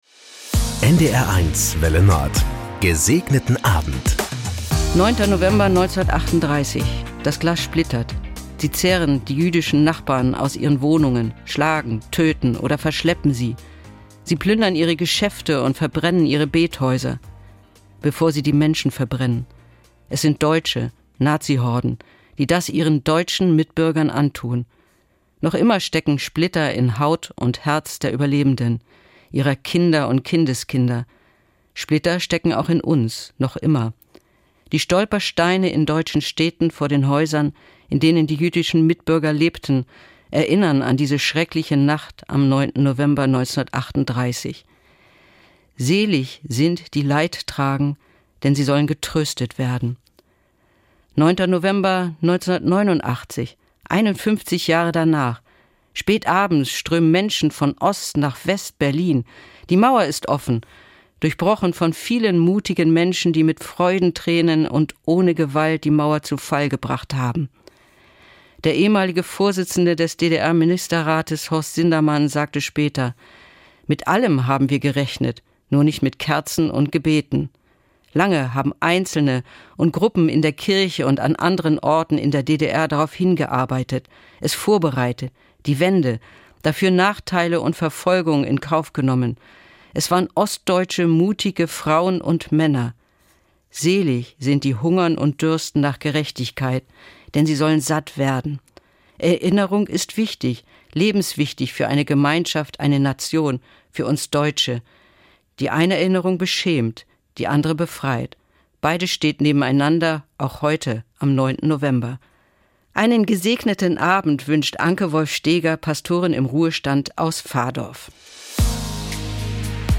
Von Sylt oder Tönning, Kiel oder Amrum kommt die Andacht als harmonischer Tagesabschluss. Täglich um 19.04 Uhr begleiten wir Sie mit einer Andacht in den Abend - ermutigend, persönlich, aktuell, politisch, tröstend.